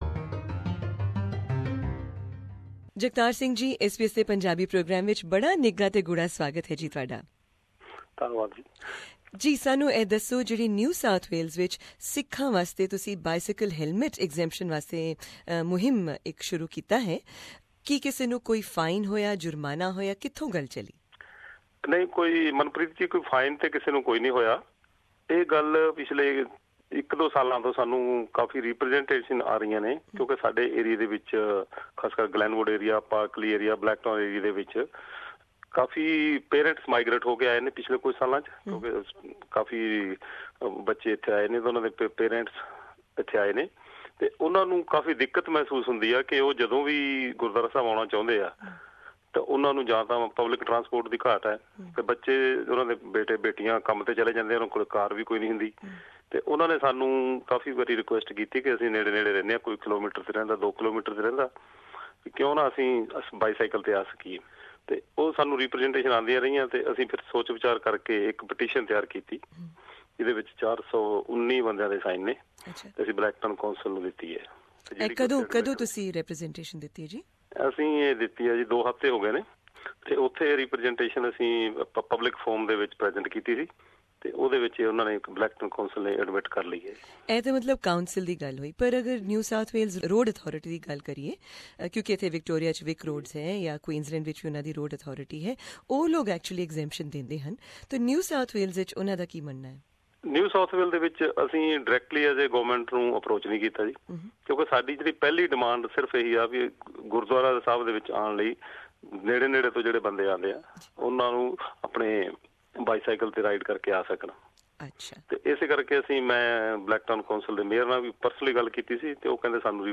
Here is a podcast of the interview